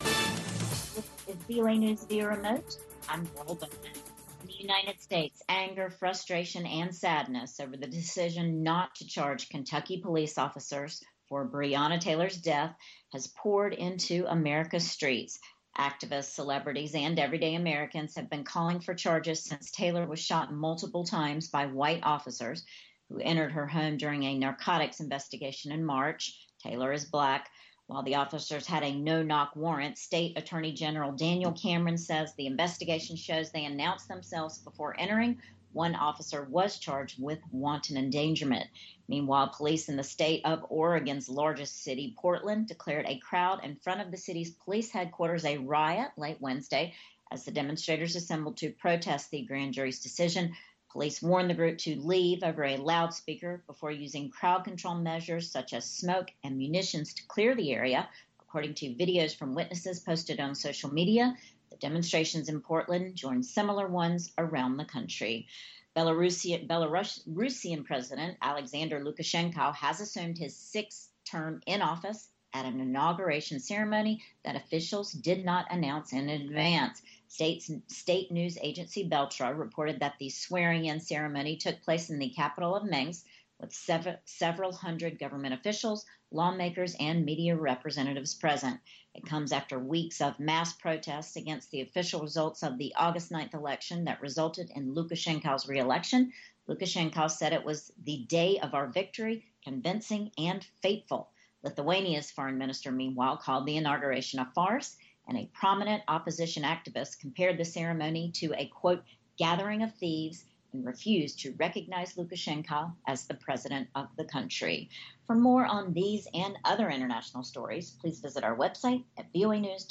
We bring you reports from our correspondents and interviews with newsmakers from across the world.